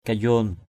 /ka-jʊo:n/ (t.) sớm = tôt. early kayuon mbluak di dak harei k_y&N O*&K d} dK hr] sớm hơn mọi ngày. earlier than usual. mai kayuon =m k_y&N đến sớm. coming...